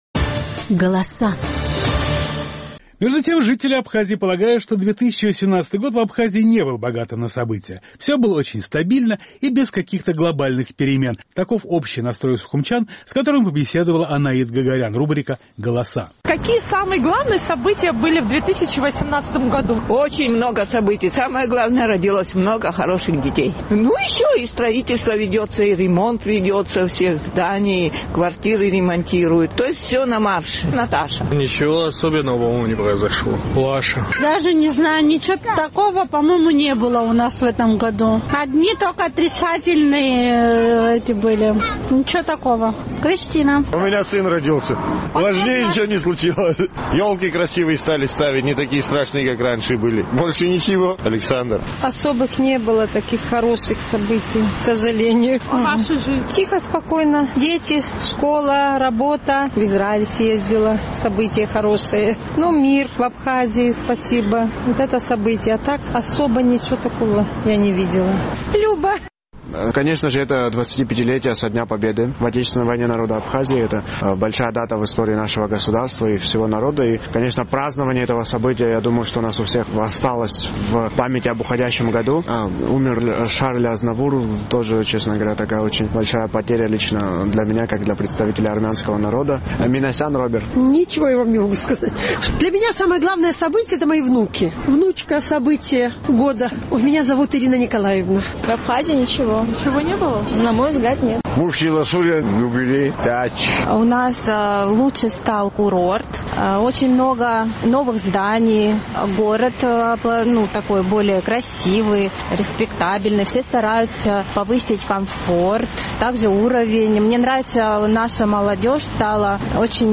Наш сухумский корреспондент попросила горожан поделиться самыми главными событиям в жизни в уходящему году.